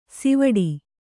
♪ sivaḍi